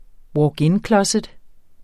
Udtale [ wɒːgˈenˌklʌsεd ]